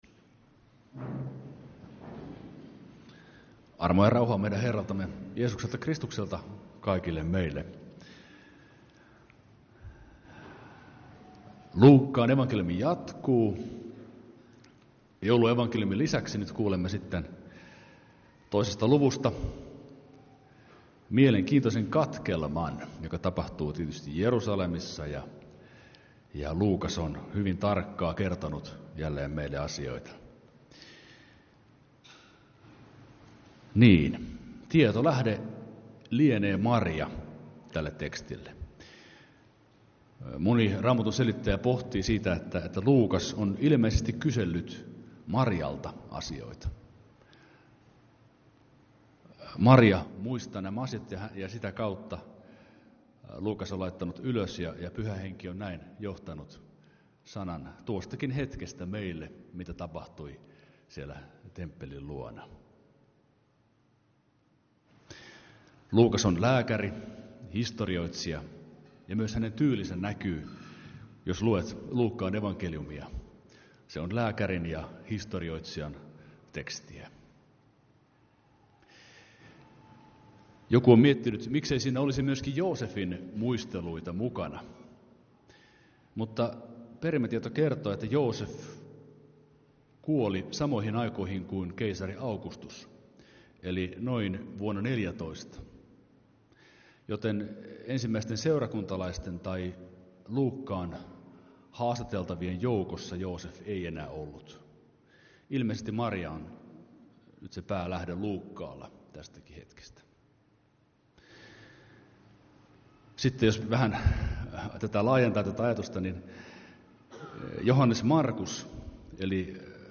Kokoelmat: Lahden lutherin kirkon saarnat